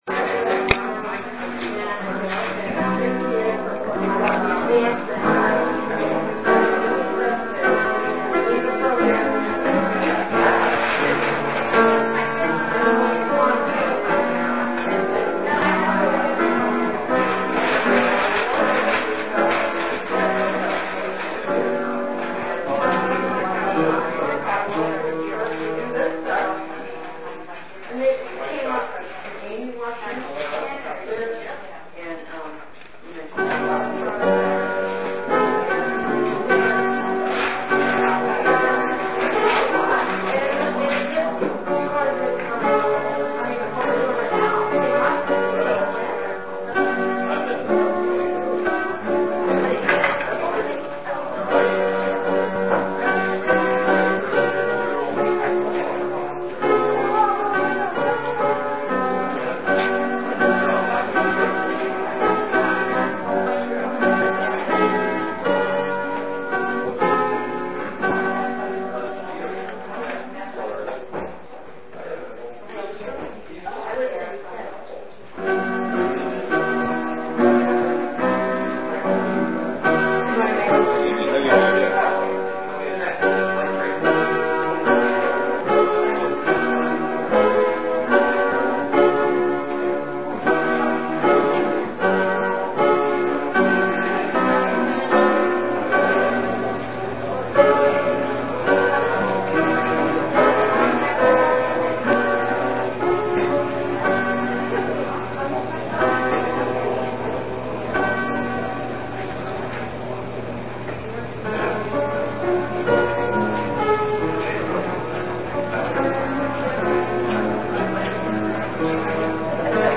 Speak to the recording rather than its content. Here are the details for our service on February 15, 2009